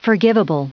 Prononciation du mot forgivable en anglais (fichier audio)